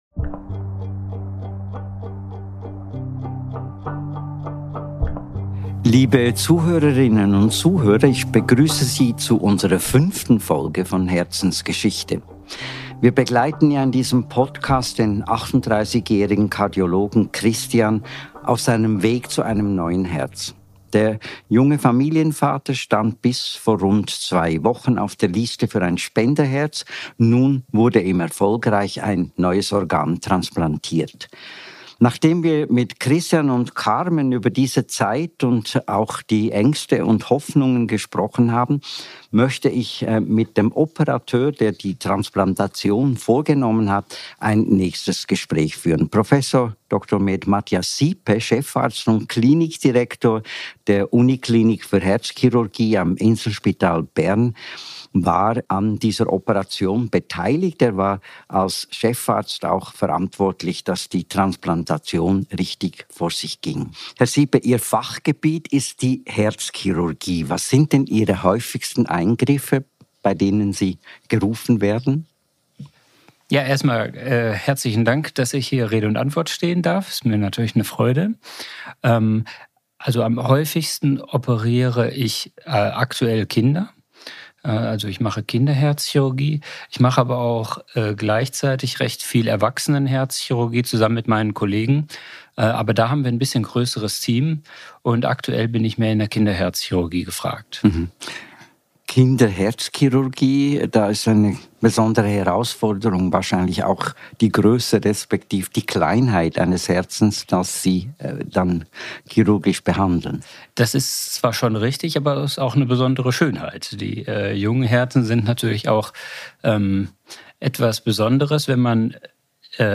In dieser Folge von Herzensgeschichte spricht er mit Kurt Aeschbacher über die Vorbereitung auf die Operation, den Transport eines Spenderherzens und wie wichtig es ist, sich mit diesem Thema zu beschäftigen.